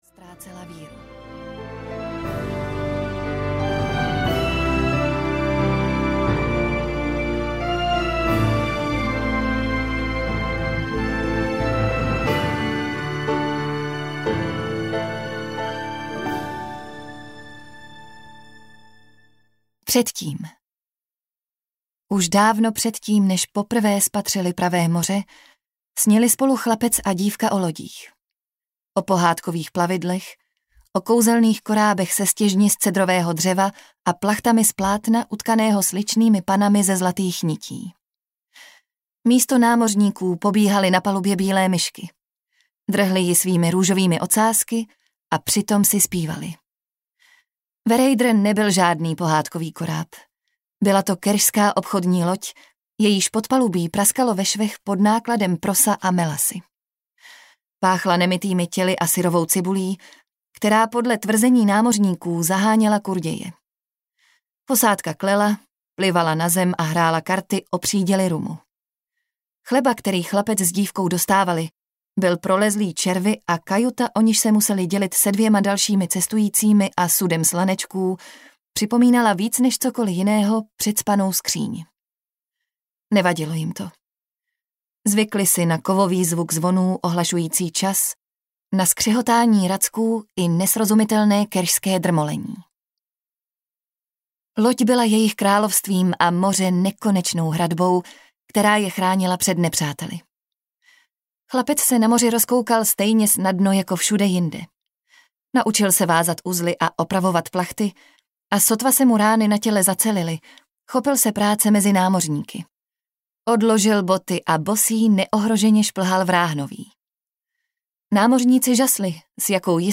Griša - Bouře a vzdor audiokniha
Ukázka z knihy